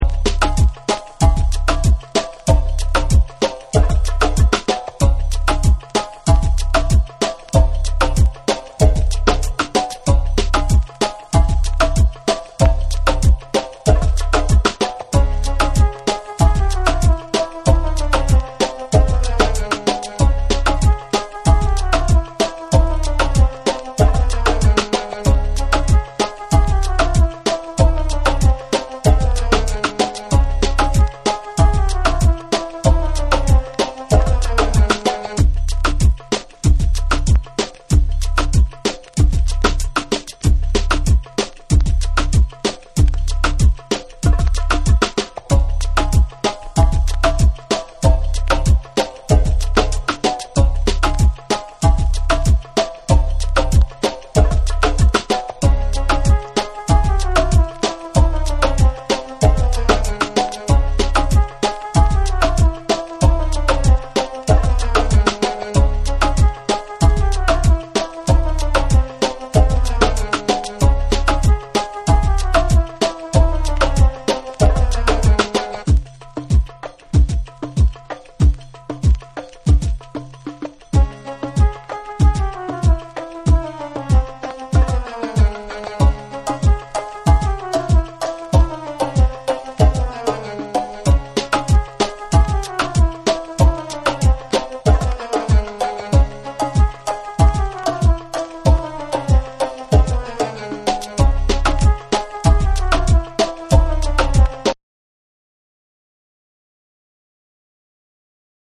勿論インストも収録しているので面白い辺境系を探してる方にもオススメです！
REGGAE & DUB / ORGANIC GROOVE / NEW RELEASE